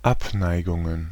Ääntäminen
Ääntäminen Tuntematon aksentti: IPA: /ˈʔapˌnaɪ̯ɡʊŋən/ Haettu sana löytyi näillä lähdekielillä: saksa Käännöksiä ei löytynyt valitulle kohdekielelle. Abneigungen on sanan Abneigung monikko.